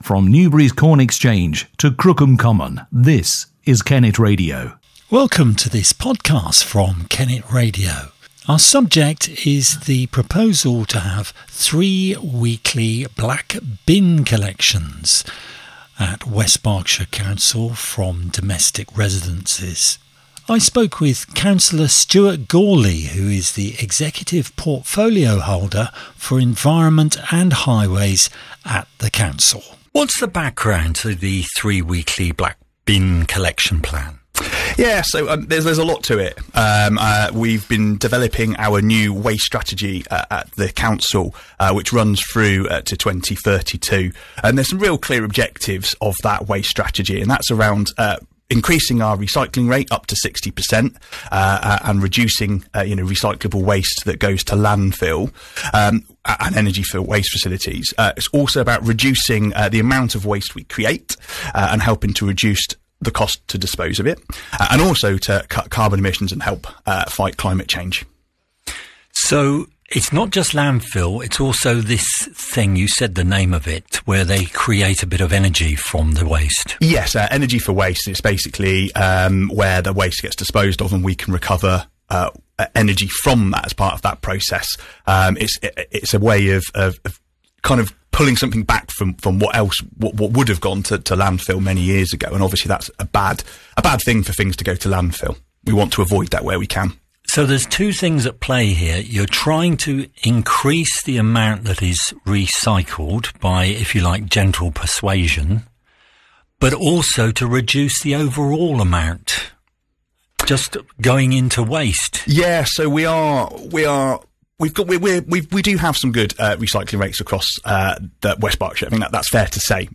In this Podcast, Councillor Stuart Gourley, the Executive portfolio holder for Environment and Highways at West Berkshire Council, answers questions about the change.